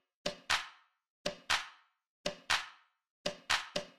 claps.mp3